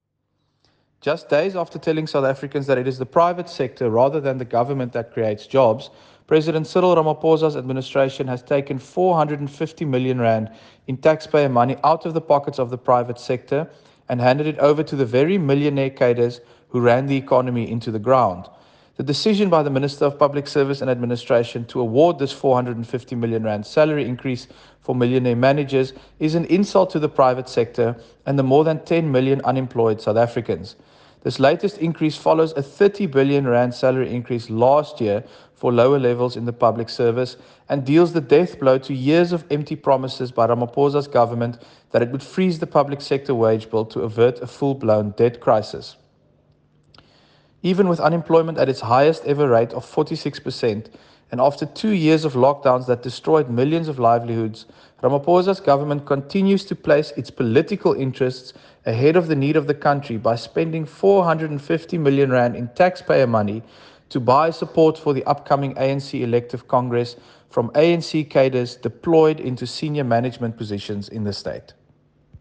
soundbite by Dr Leon Schreiber MP.